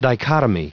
Prononciation du mot dichotomy en anglais (fichier audio)
Prononciation du mot : dichotomy